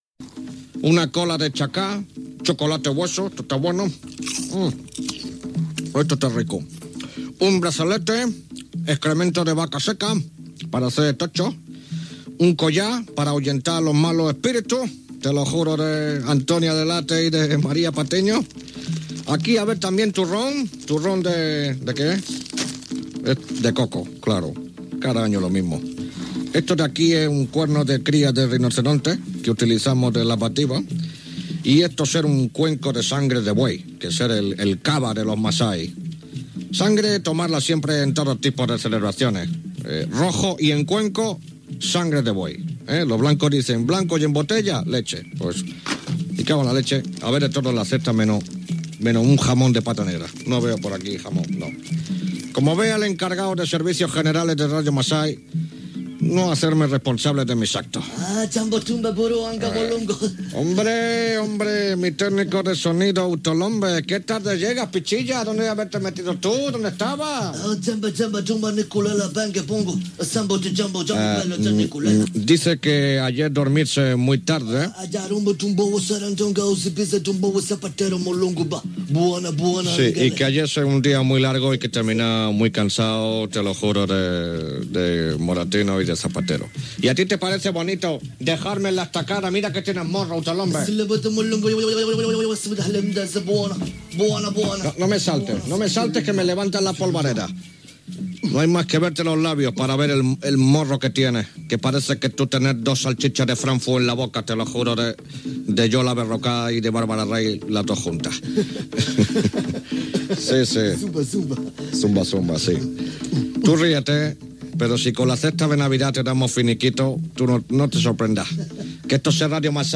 Final d'un programa amb la secció humorística "Radio Masai", amb esment a la rifa de Nadal
Entreteniment
FM